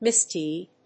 音節mis･deed発音記号・読み方mɪsdíːd
• / ˈmɪˈsdid(米国英語)
• / ˈmɪˈsdi:d(英国英語)